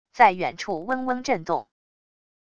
在远处嗡嗡震动wav音频